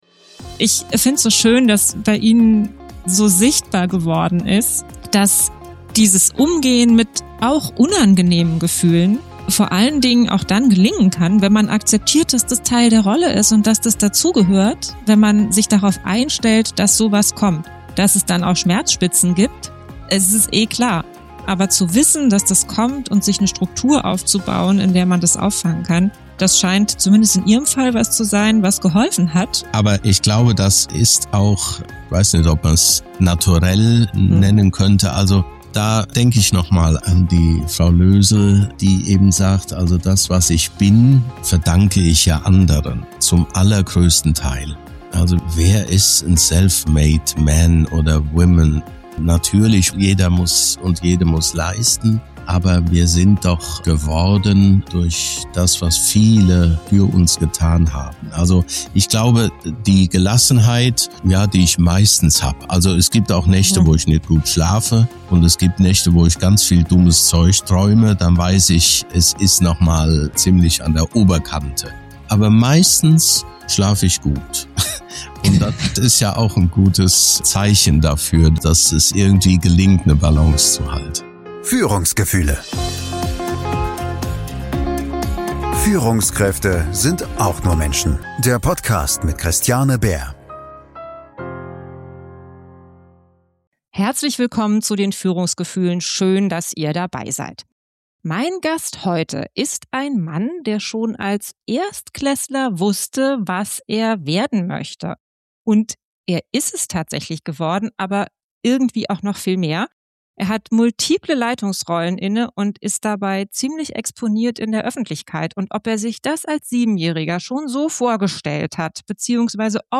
Beschreibung vor 3 Tagen In dieser Episode spreche ich mit Bischof Dr. Georg Bätzing über Führung in einer ungewöhnlichen Führungsrolle: Dem Bischofsamt.